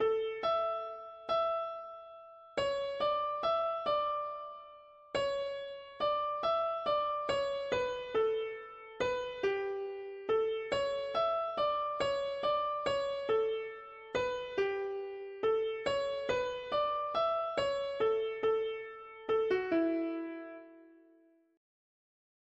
Let not grief sour your g… english greek secular 1part death
Key: A mixolydian Meter: irregular